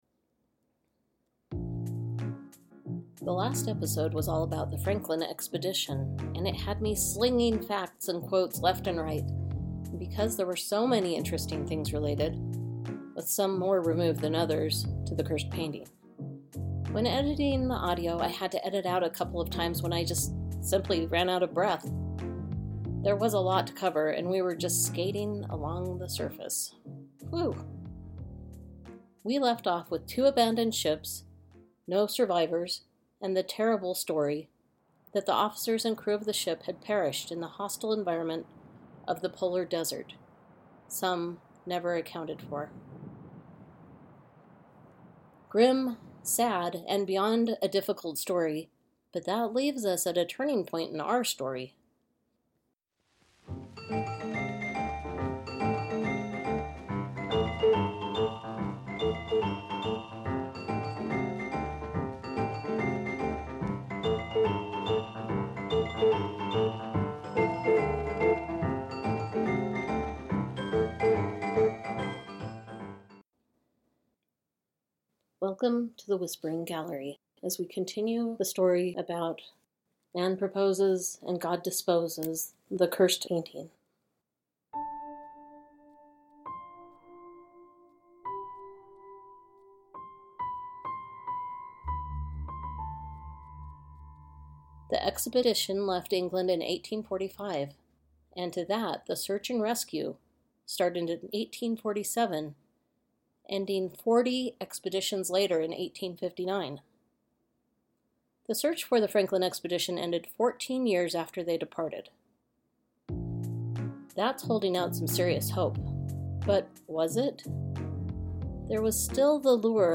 When editing the audio I had to edit out a couple of times when I had run out of breath! There was simply a lot to cover and we were just skating along the surface.